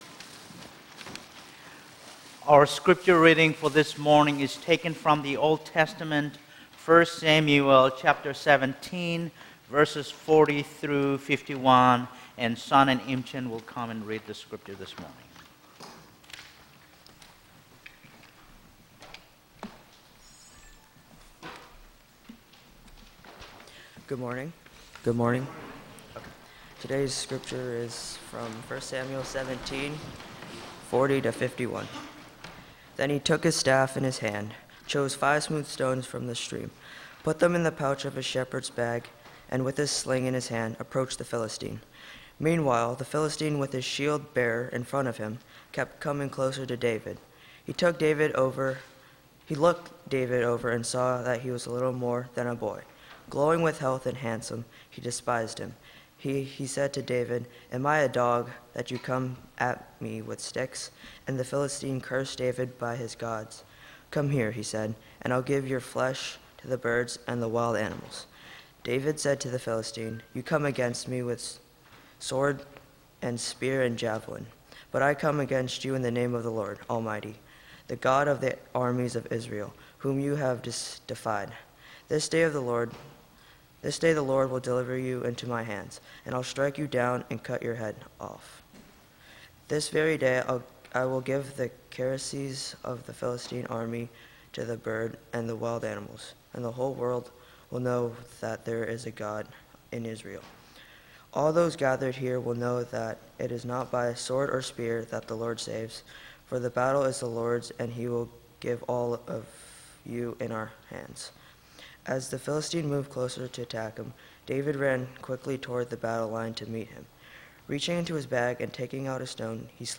Audio Recording of June 14th Worship Service – Now Available
The audio recording of our latest Worship Service is now available.